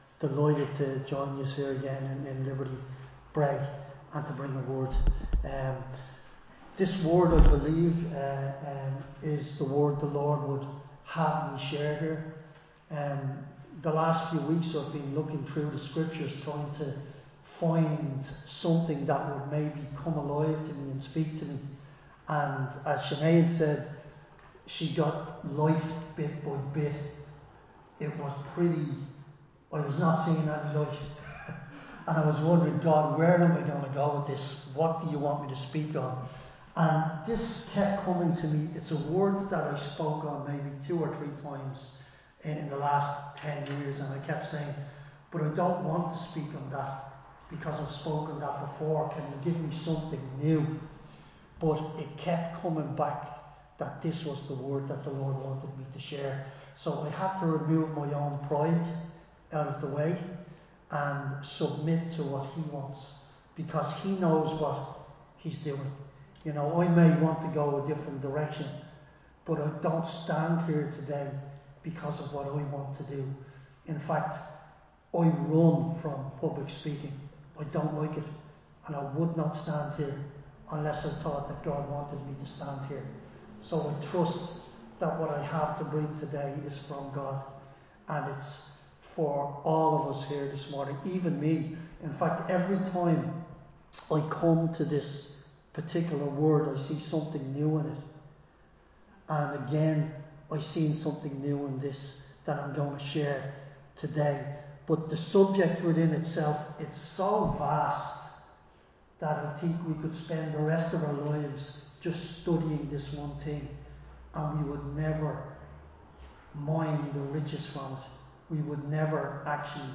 Recorded live in Liberty Church on 12 October 2025